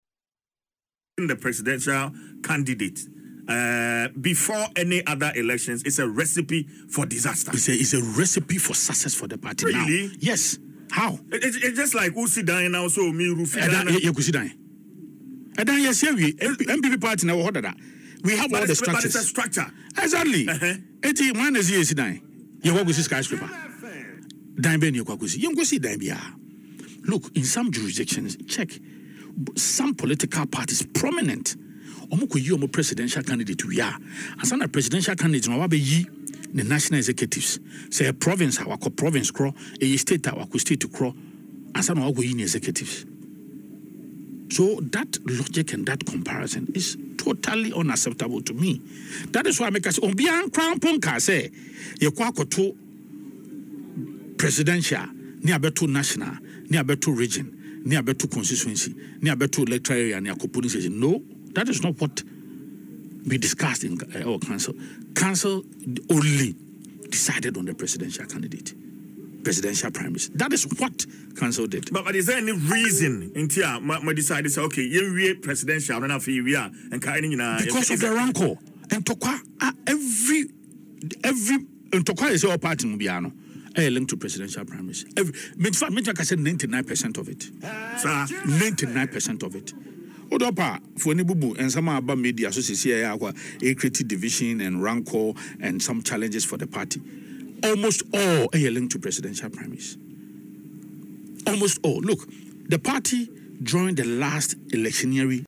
in an interview on Adom FM’s Dwaso Nsem